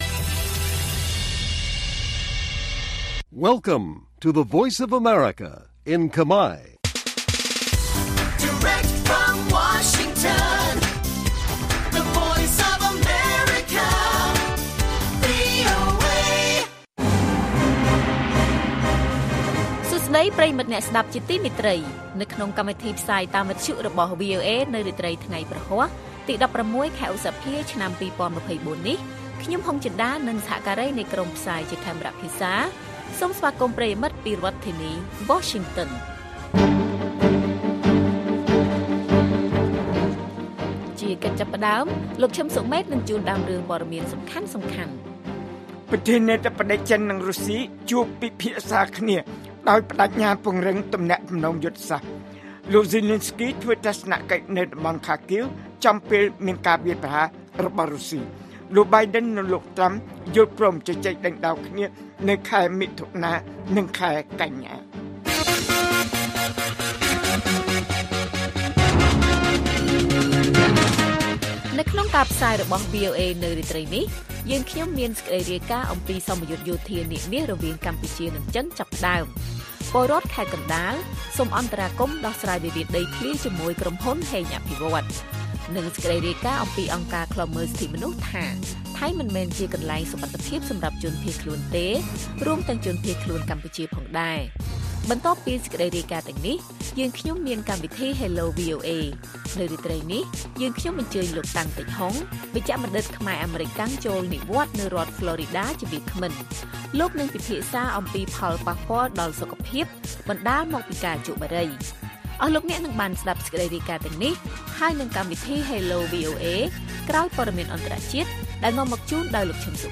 ព័ត៌មានពេលរាត្រី ១៦ ឧសភា៖ សមយុទ្ធយោធា«នាគមាស»រវាងកម្ពុជានិងចិនចាប់ផ្តើម